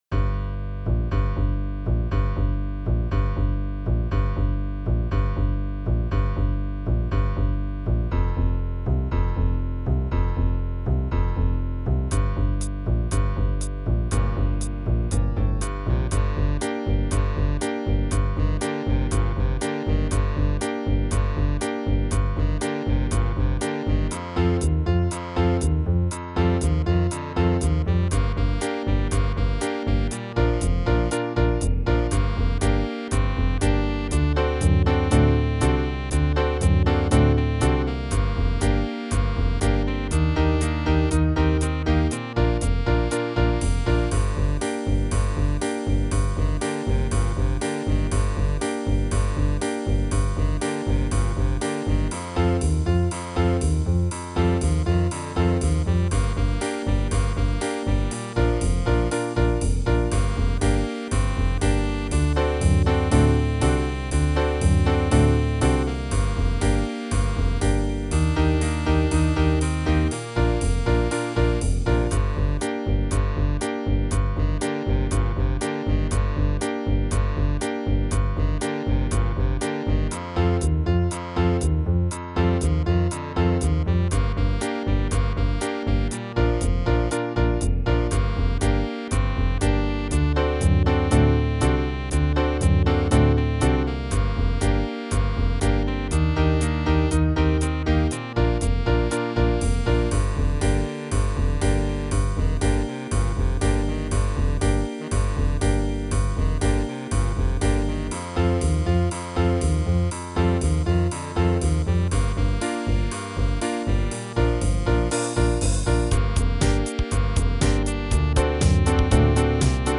• Le fichier son, AVEC la mélodie très FAIBLE ==>